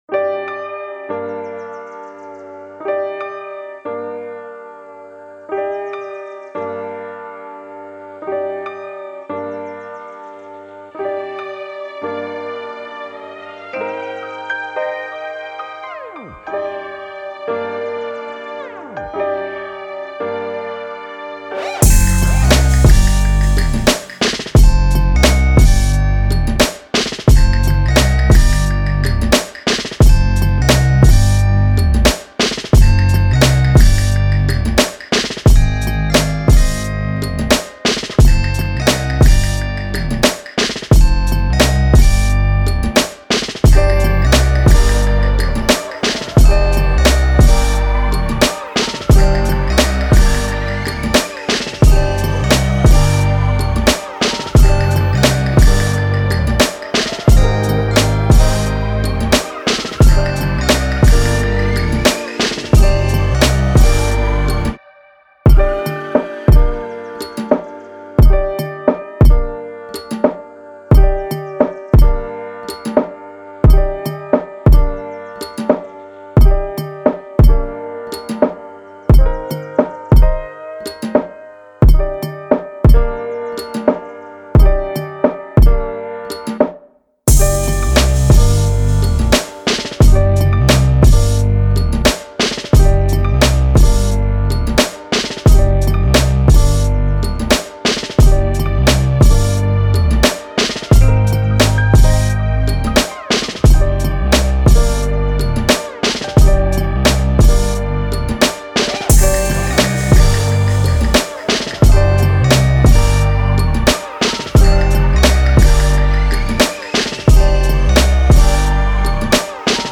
This is the official instrumental
Rap Instrumentals